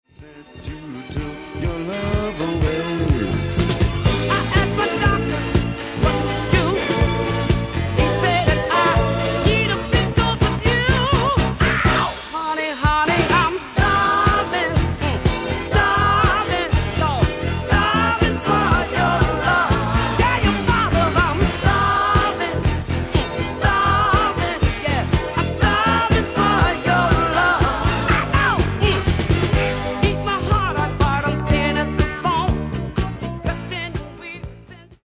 Genere:   Disco | Funk | Philly Sound